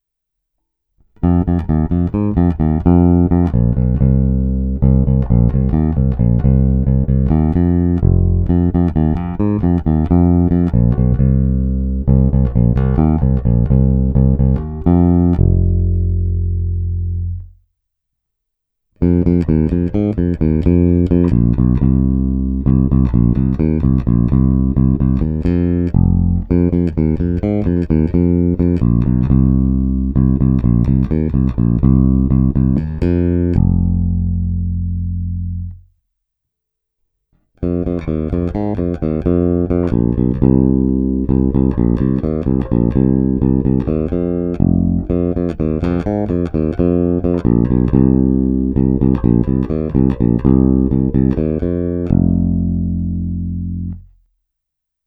Není-li uvedeno jinak, jsou ukázky nahrány rovnou do zvukové karty a jen normalizovány.